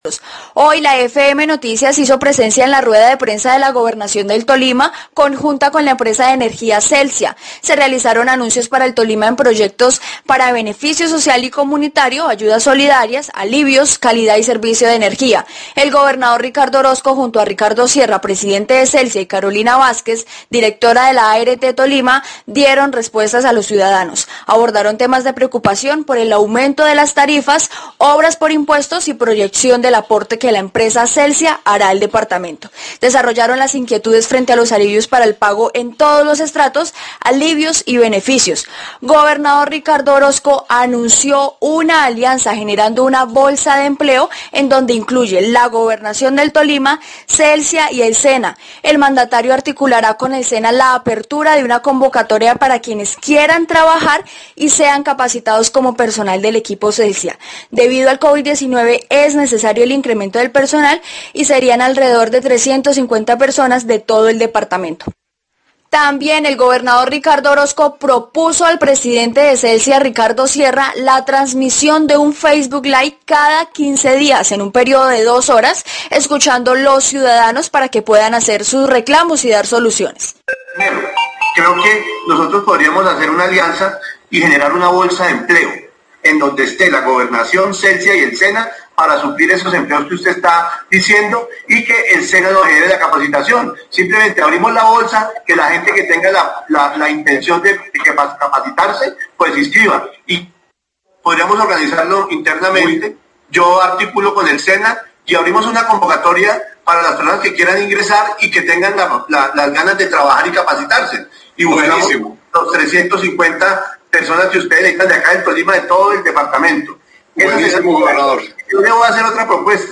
Gobernación del Tolima, Celsia y la ART dieron rueda de prensa a los tolimenses hoy
Radio